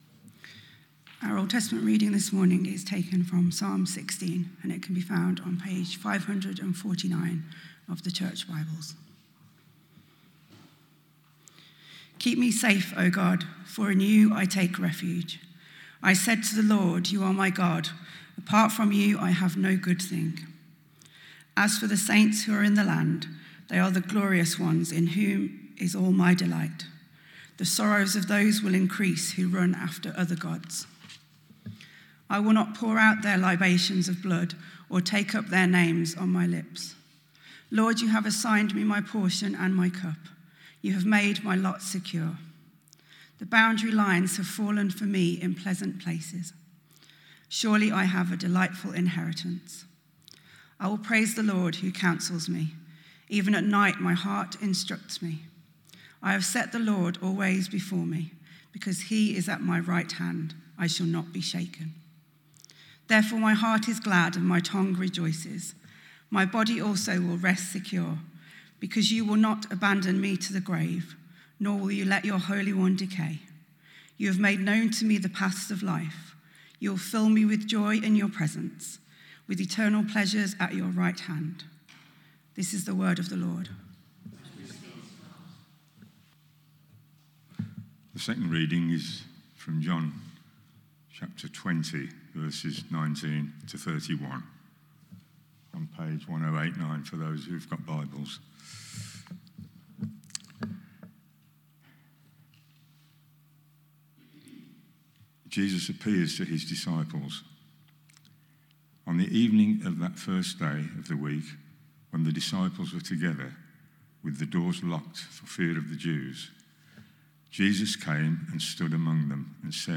Sermons - St Marys Wythall